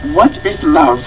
: Grover asks what love is.